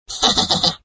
SBobLaugh.ogg